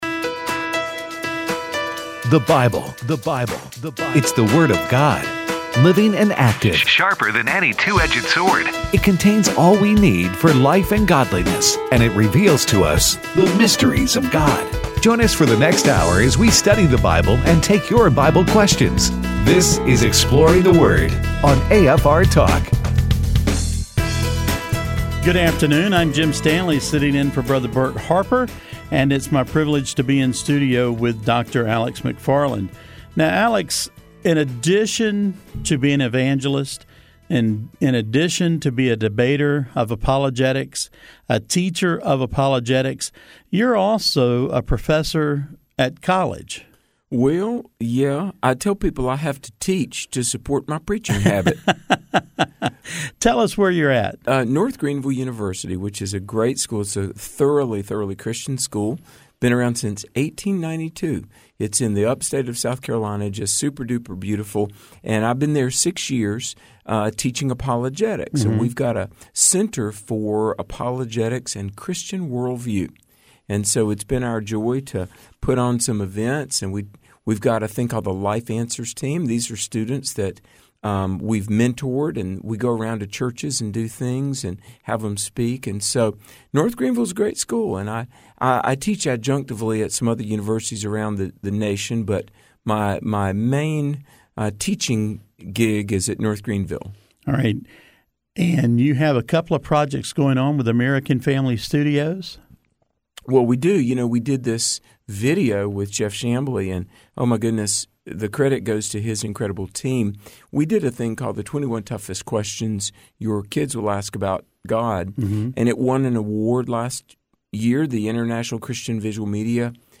pre-recorded show.